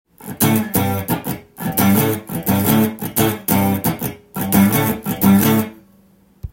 例えばコードがAmの場合　６弦オクターブ奏法を使用して伴奏を作ると
このようにリズムに変化をつけて左に５フレットと３フレット行ったり来たりするだけで
カッコいい伴奏のようなリフを作ることが出来ます。